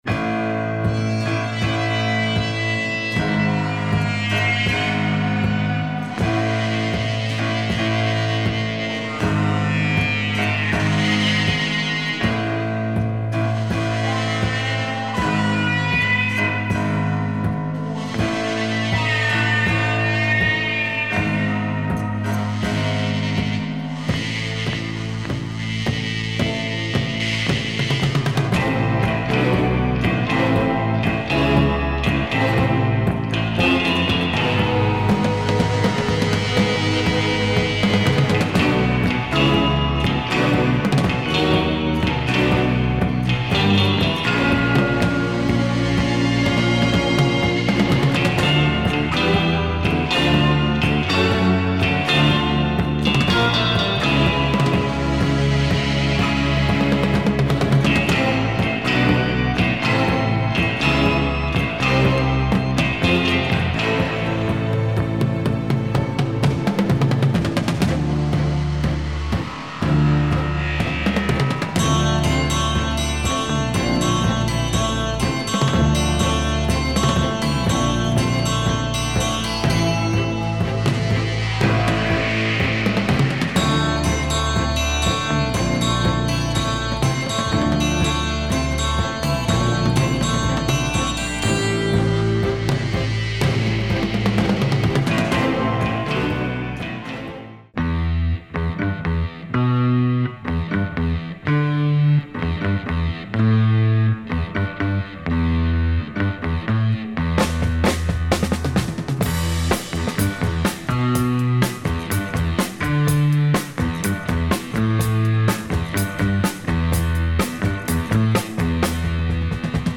Tense Italian crime groove